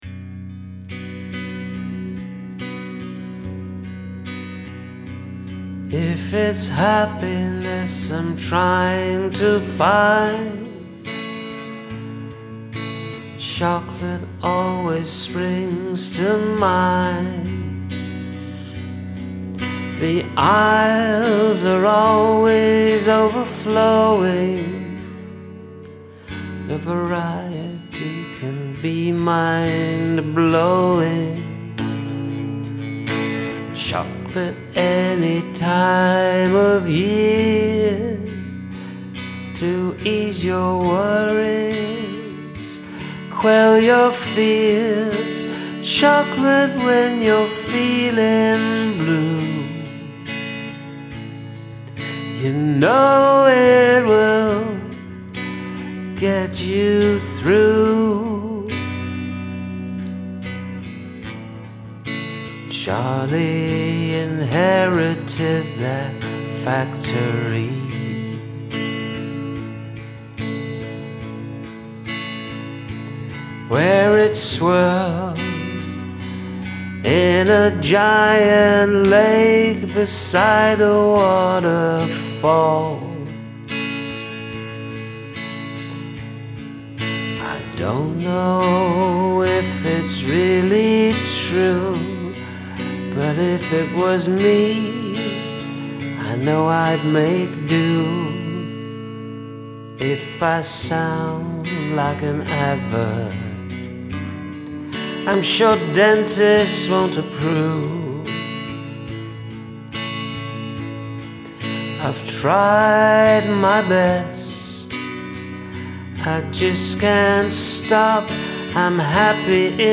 This a really tasty little song, love it.
A beautiful ballad, brilliantly performed.